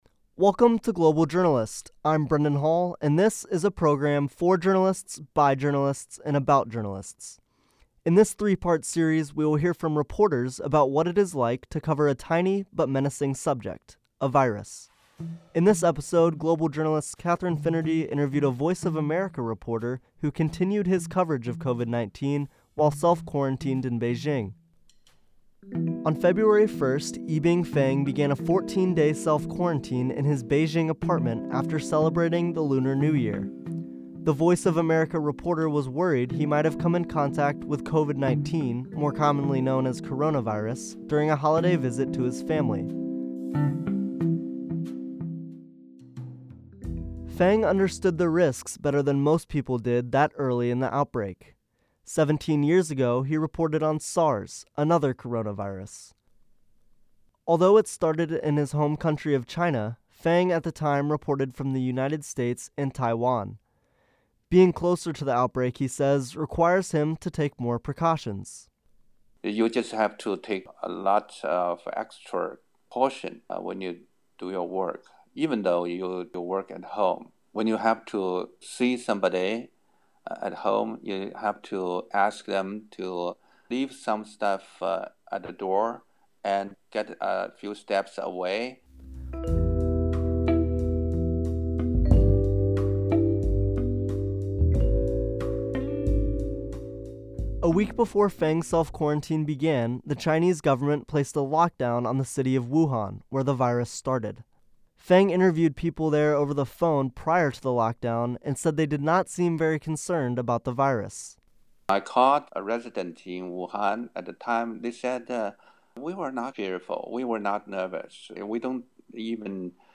On this February 28, 2020 program, VOA journalists discuss reporting on COVID-19 from China and the ways the Chinese government restricts information journalists and whistleblowers are allowed to share with the public.